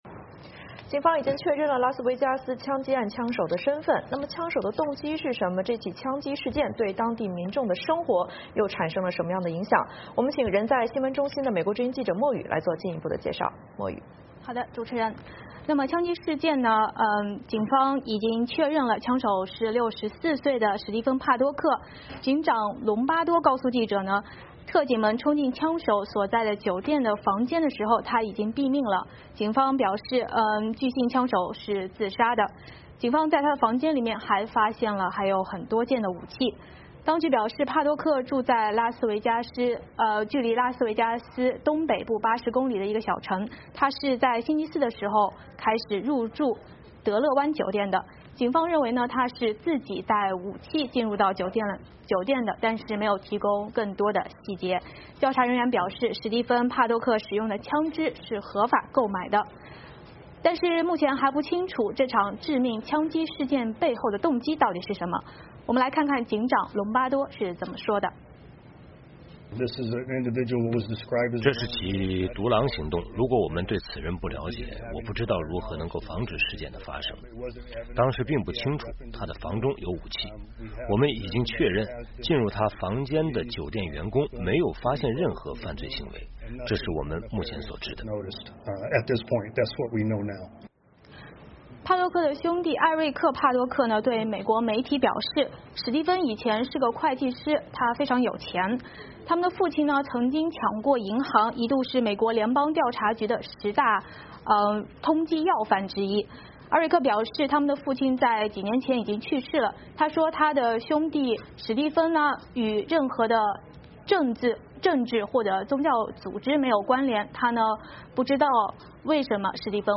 VOA连线：警方调查拉斯维加斯枪击案枪手身份